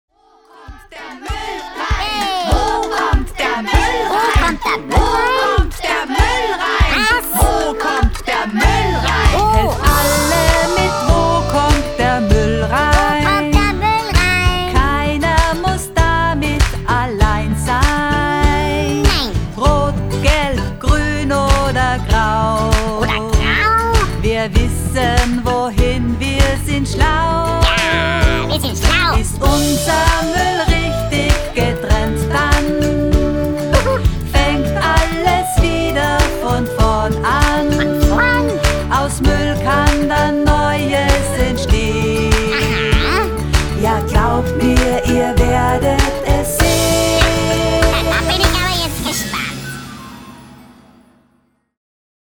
mitreißende Song